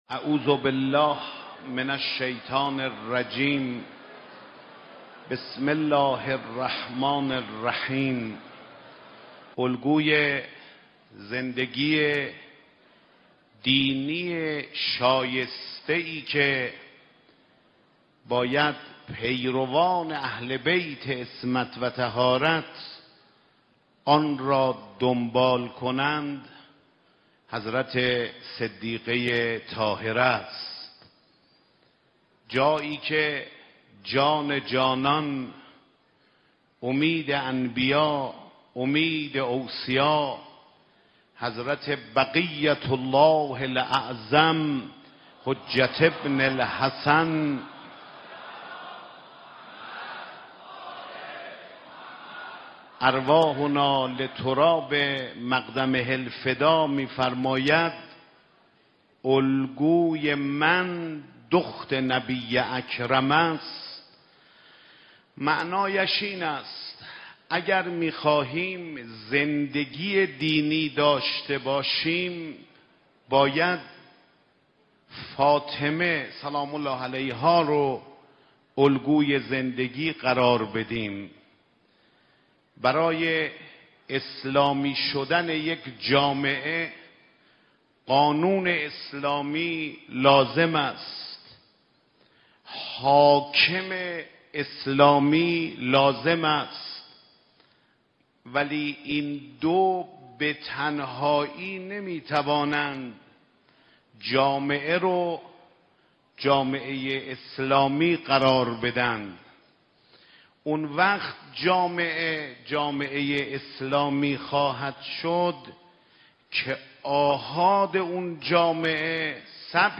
سخنرانی حجت الاسلام سید احمد خاتمی با موضوع تجربه ی زندگی دینی در پرتو الگو پذیری از حضرت فاطمه (س)